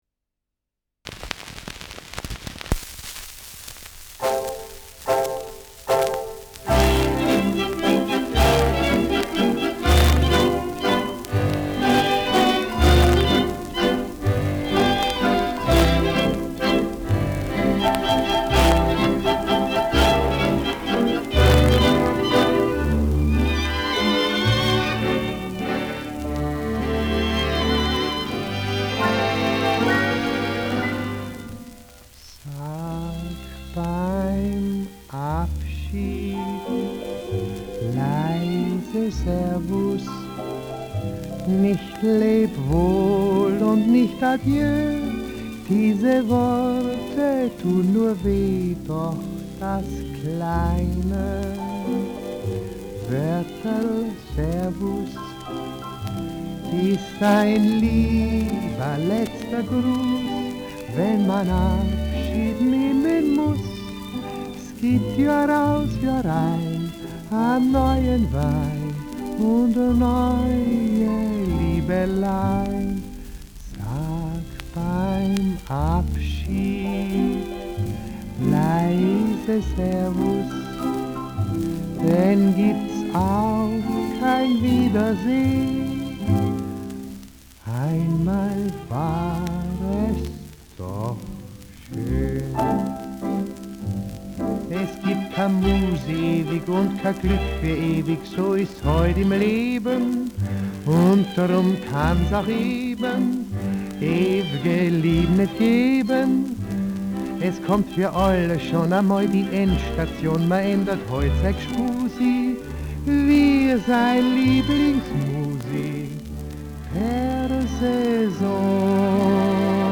Schellackplatte
[unbekanntes Ensemble] (Interpretation)